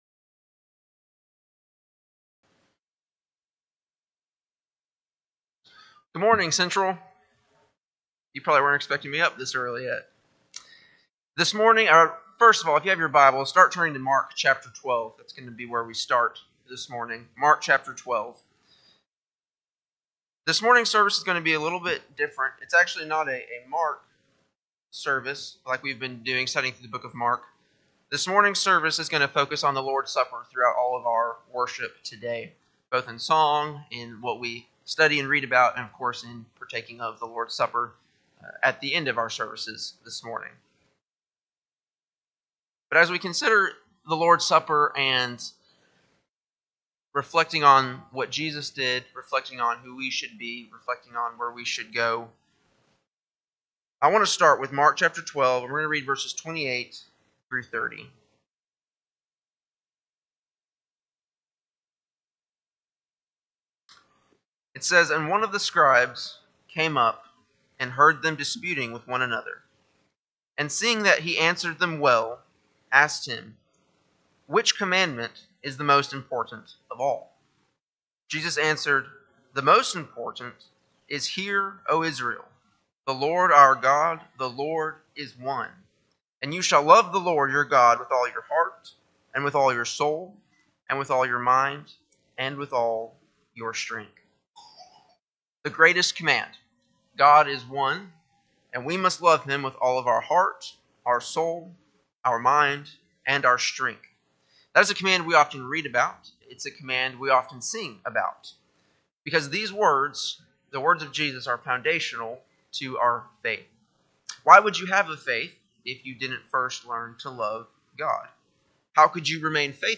Sunday AM Sermon
Lord’s Supper Service
4-21-24-Sunday-AM-Sermon.mp3